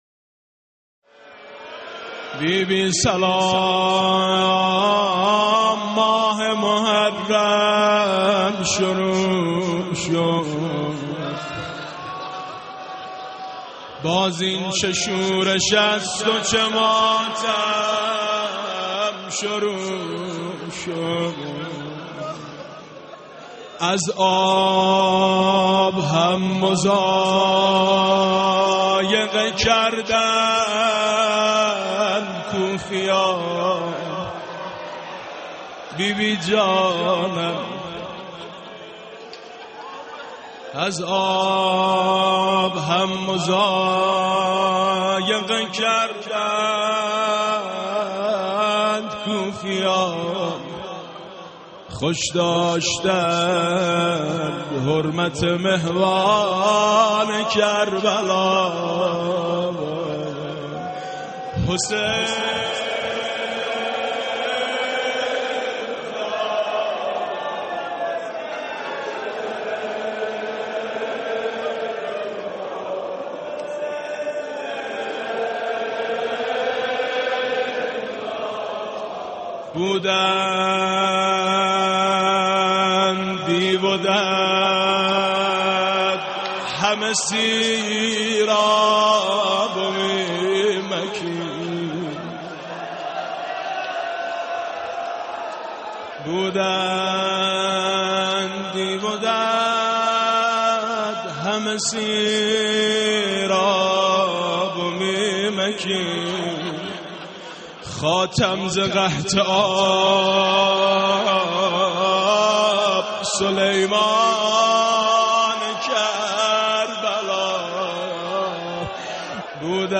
دانلود گلچین مداحی های محرم+ فایل صوتی
به مناسبت ایام محرم (ورود به محرم)